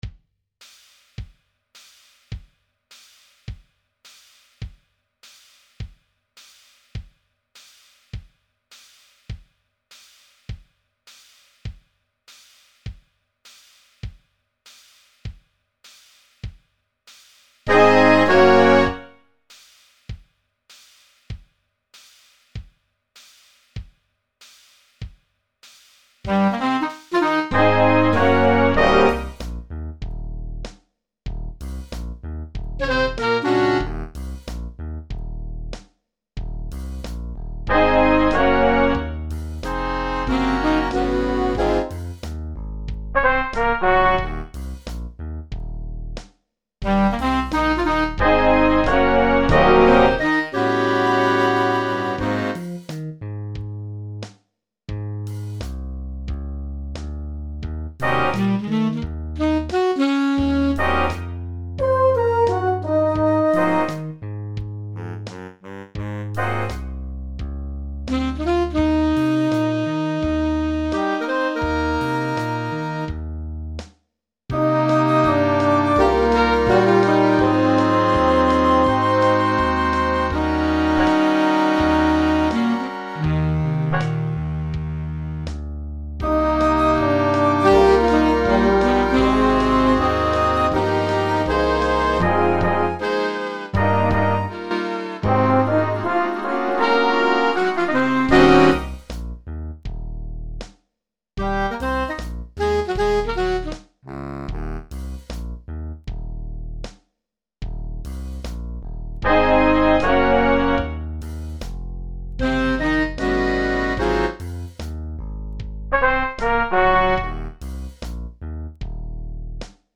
Altsax 1     Altsax 2       Tenorsax 1         Tenorsax 2        Barytonsax
Trumpet 1   Trumpet 2     Trumpet 3      Trumpet 4
Trombon 1   Trombon 2    Trombon 3     Trombon 4       Tuba
Bas          Gitarr          Piano           Trumset        Perkussion